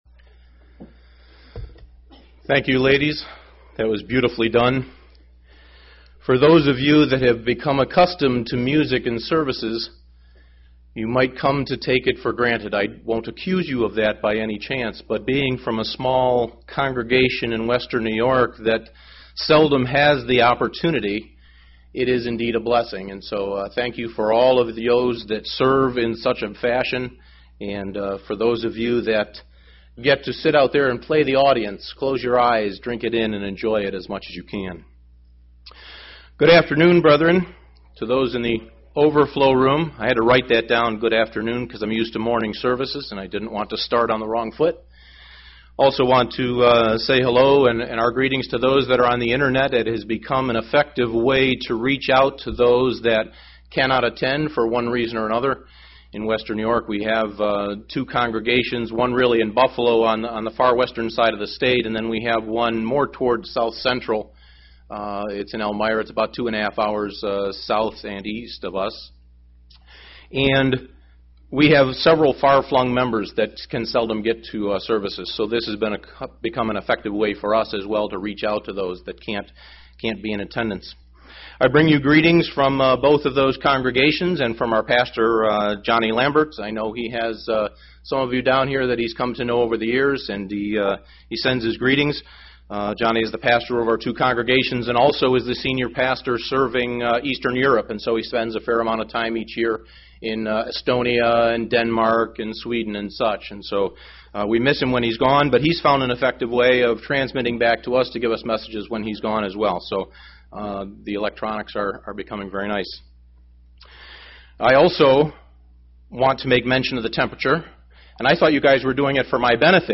Given in Tampa, FL Elmira, NY Buffalo, NY
UCG Sermon Studying the bible?